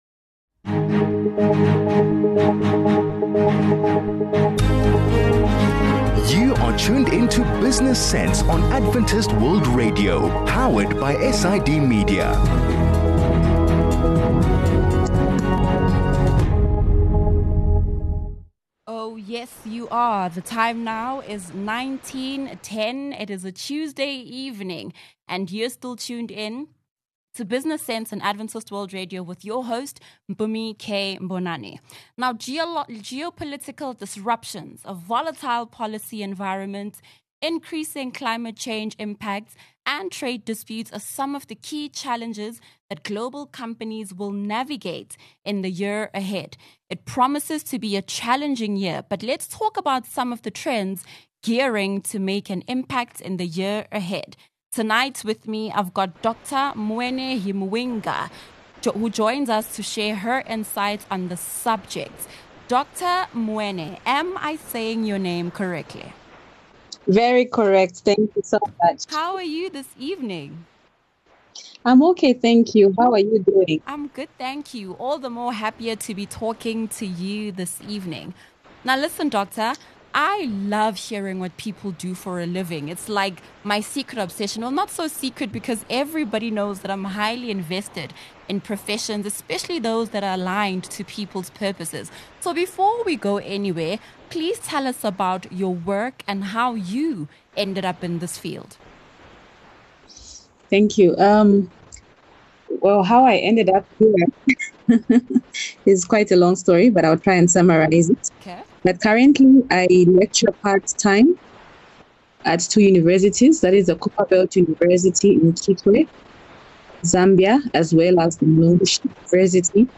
A conversation about some of the environmental sustainability trends gearing to make an impact in the year ahead.